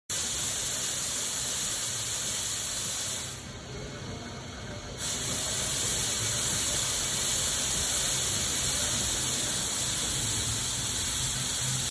MK F02 X20 Fog Jet offers sound effects free download
MK-F02 X20 Fog Jet offers instant burst effects similar to a CO2 cannon but doesn’t require a CO2 tank. It uses specialized fog fluid, so it is the most convenient option for events.